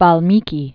(väl-mēkē) fl. c. 300 BC.